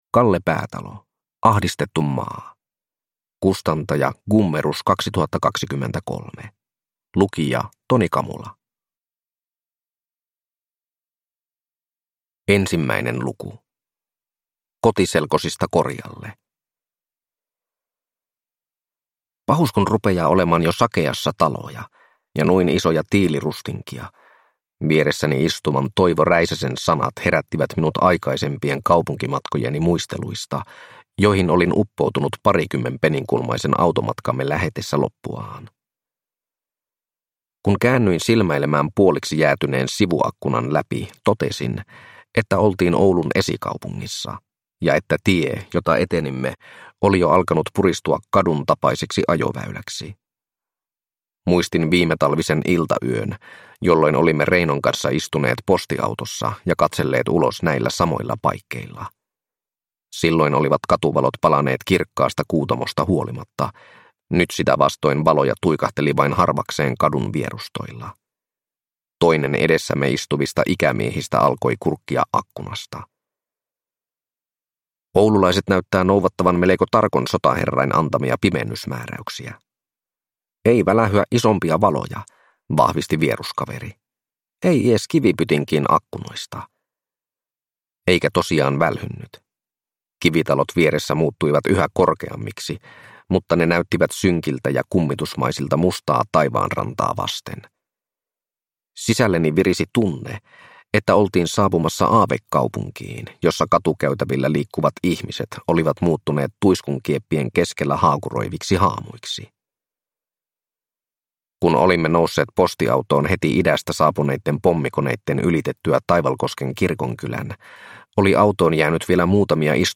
Ahdistettu maa – Ljudbok